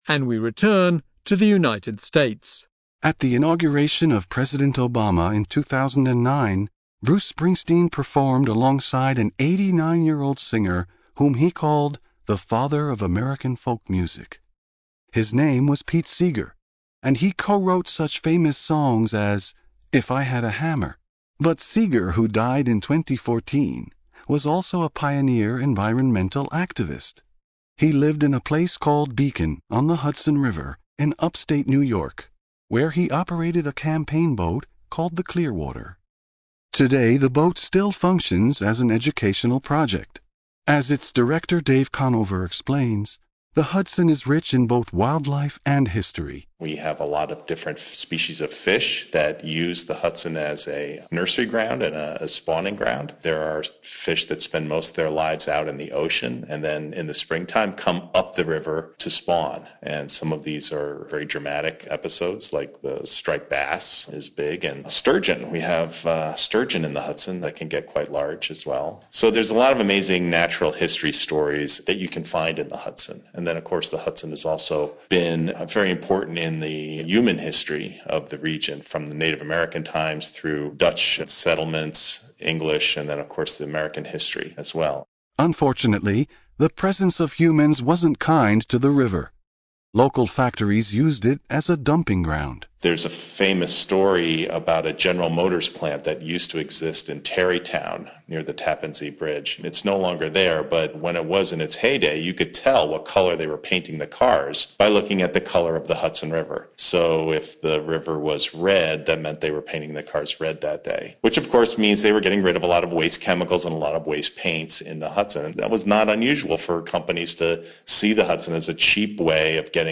Interview: if i had a river